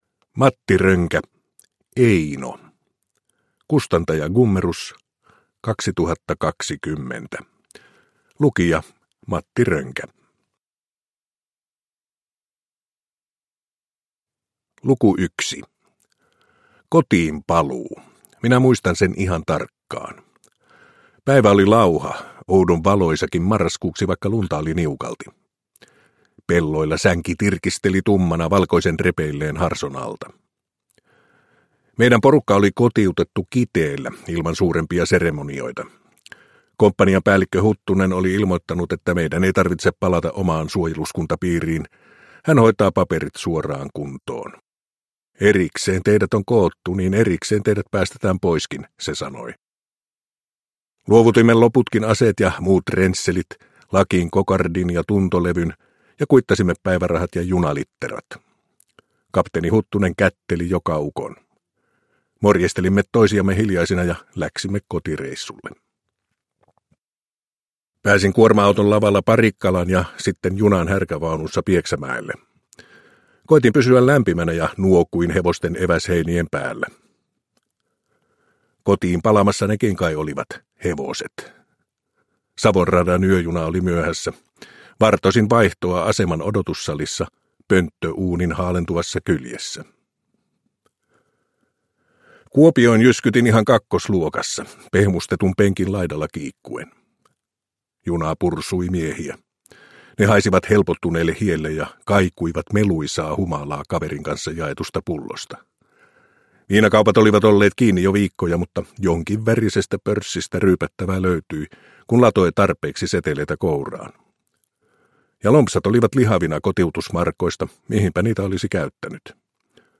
Produkttyp: Digitala böcker
Matti Röngän itse lukema äänikirja on romaani nuoruudesta ja odottamattomista, kohtuuttomilta tuntuvista valinnan paikoista.
Uppläsare: Matti Rönkä